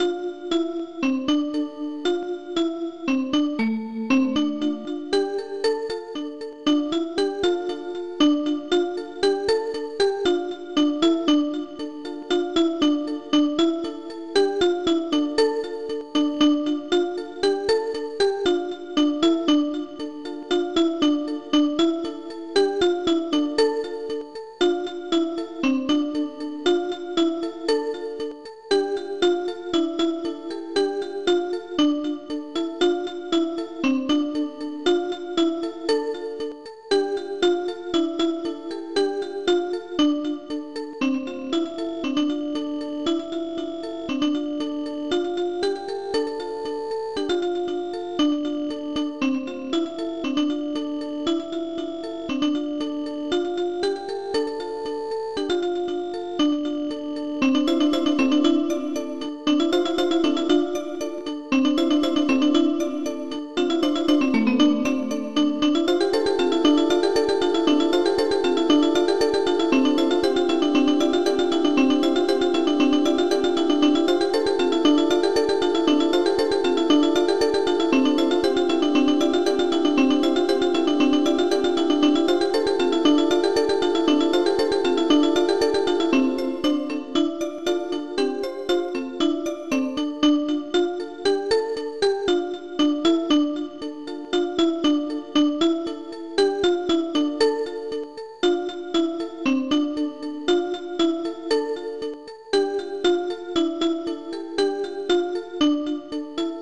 Composer 669 Module
Type 669 (Composer 669) Tracker Composer 669 Tracks 8 Samples 2 Patterns 11 Instruments lead-bell-00 lead-bell-00 ............*.Culture.*..........